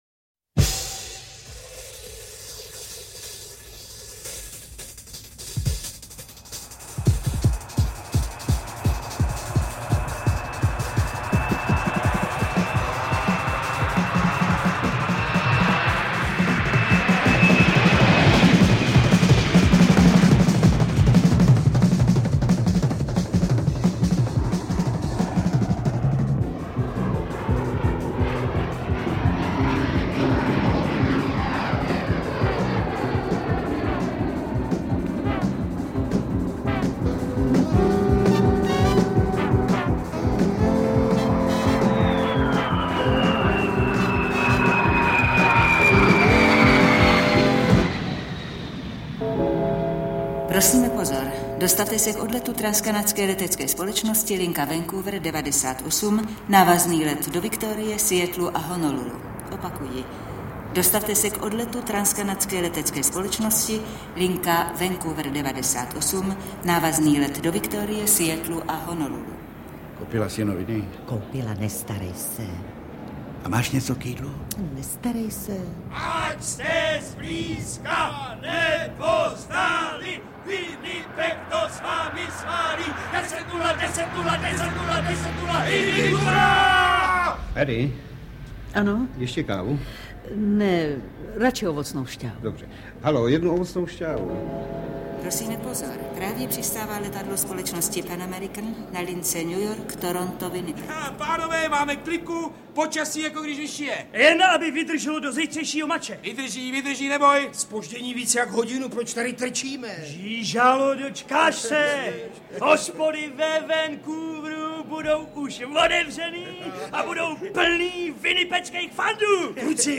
Interpreti:  Jiří Adamíra, Eduard Cupák, Vladimír Čech, Ladislav Potměšil, Gabriela Vránová
Mimořádně zdařilé rozhlasové zpracování napínavého dramatu odehrávajícího se na palubě letadla na trase Winnipeg – Vancouver.